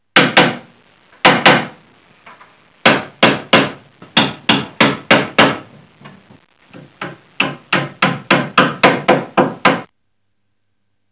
Hammer2
HAMMER2.wav